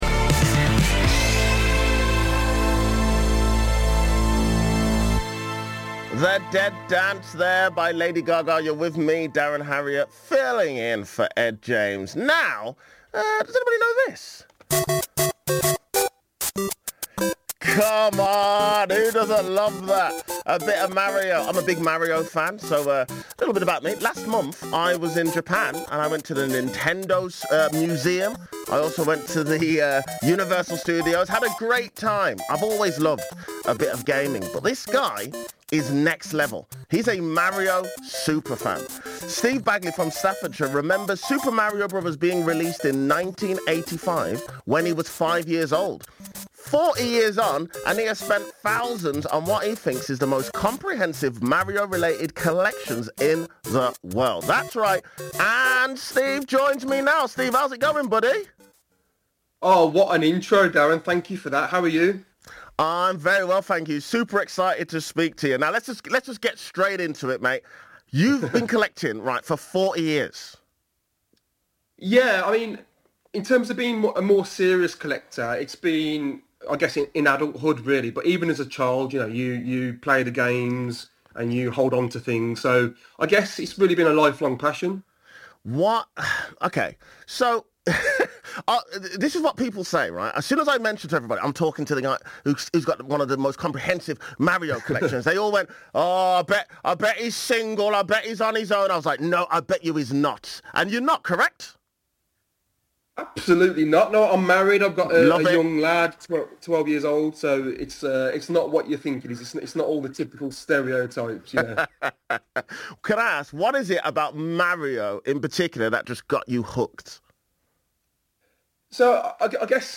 24 September 2025 – Radio – Interview (BBC Radio WM /